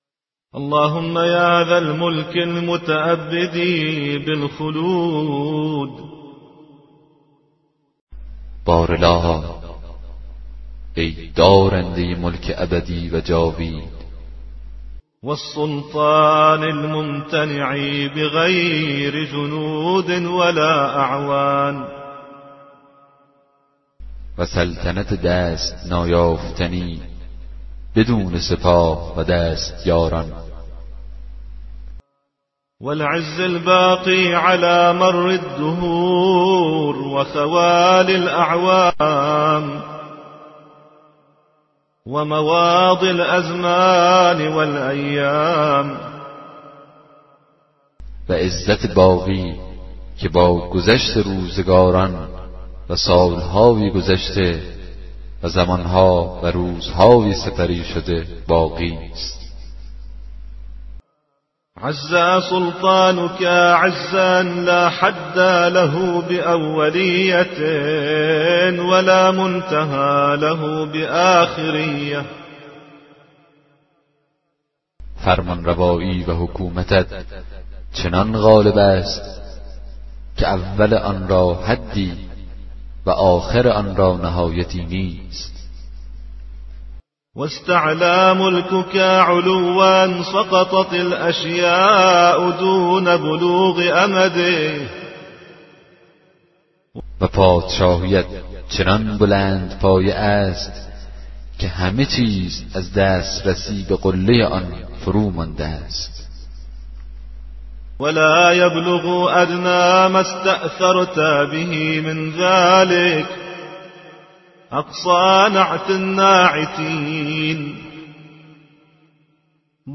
کتاب صوتی دعای 32 صحیفه سجادیه